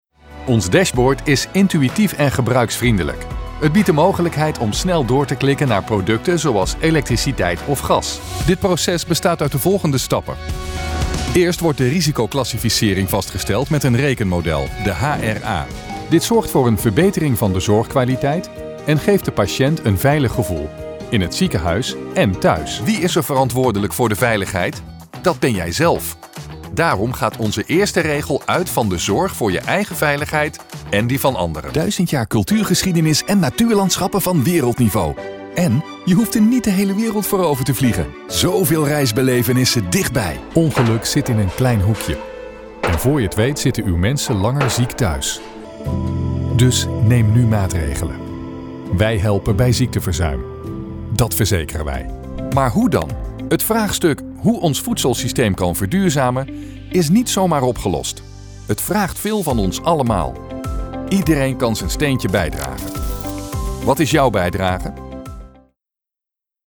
Reliable, Commercial, Deep, Friendly, Corporate
Corporate